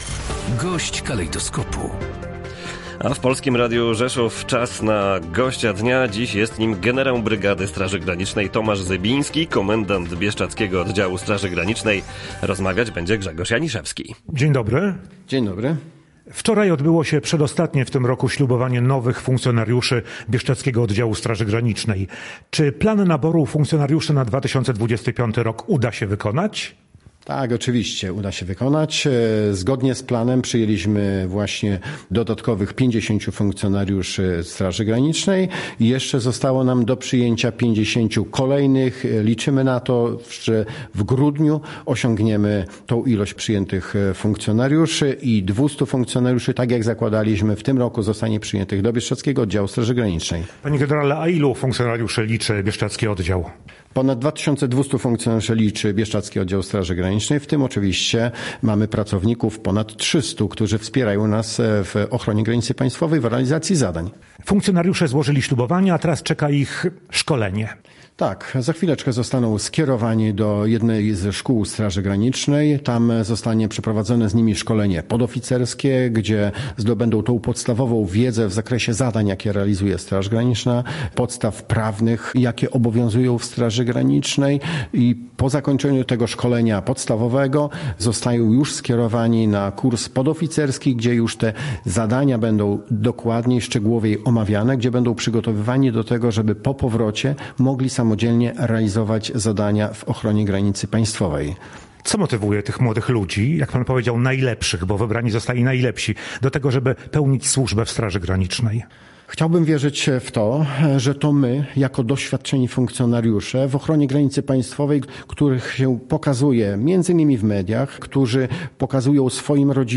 Jak ocenił gość Polskiego Radia Rzeszów, generał brygady Tomasz Zybiński, komendant Bieszczadzkiego Oddziału Straży Granicznej, motywacja nowych rekrutów może wynikać z pobudek patriotycznych i tego, że ta formacja dobrze wywiązuje się ze swoich obowiązków.